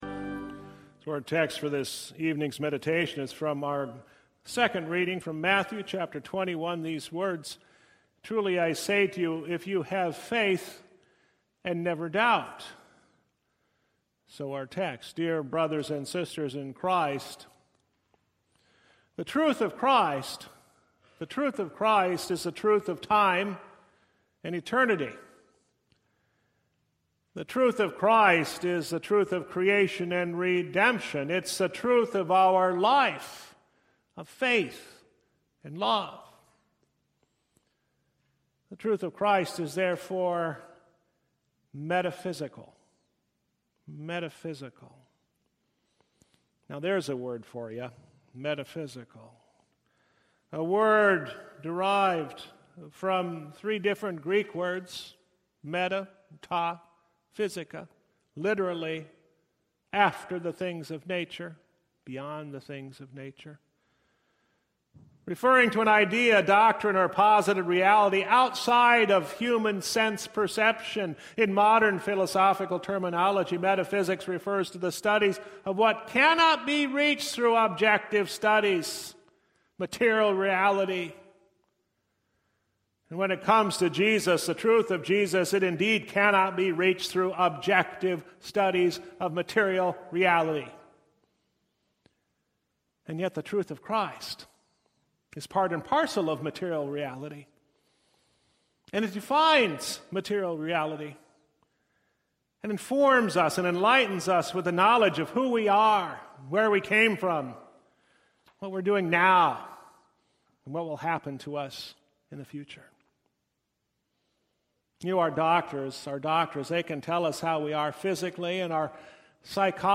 Lenten-Midweek-2-2023.mp3